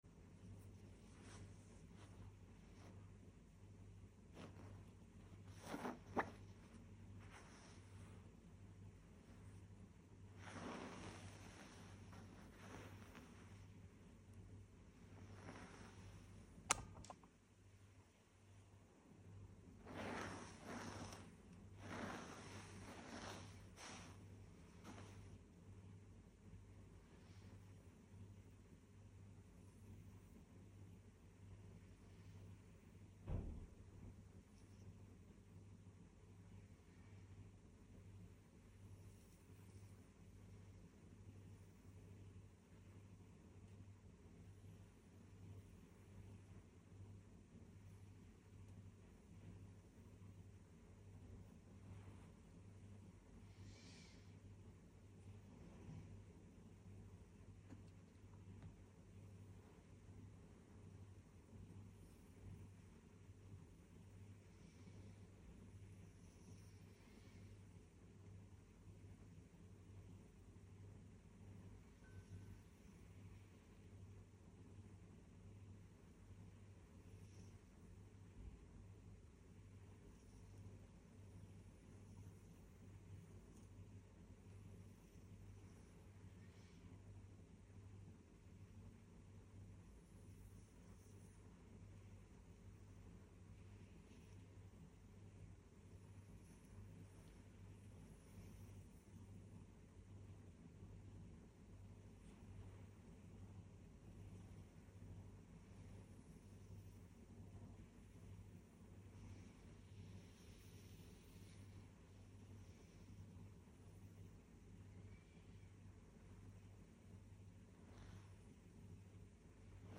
You Dm To Book Asmr Sound Effects Free Download